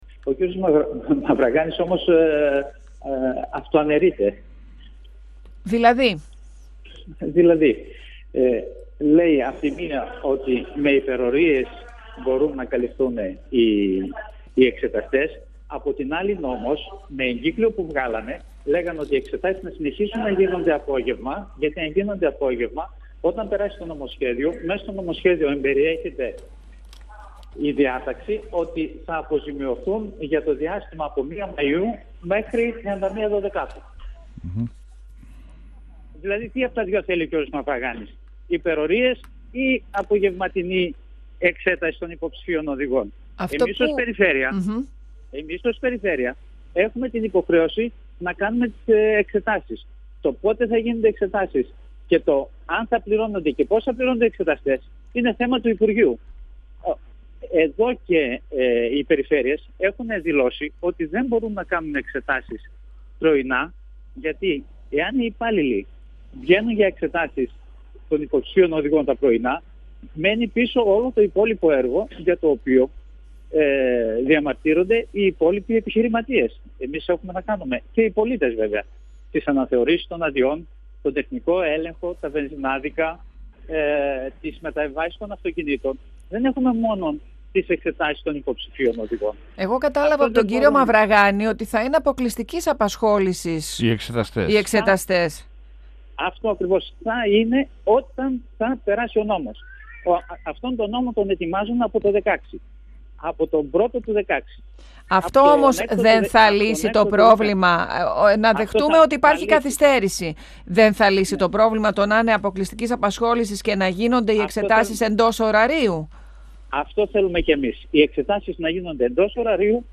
Ο αντιπεριφερειάρχης αρμόδιος για θέματα Μεταφορών στην Περιφέρεια Κεντρικής Μακεδονίας, Κωνσταντίνος Πάλλας, στον 102FM του Ρ.Σ.Μ. της ΕΡΤ3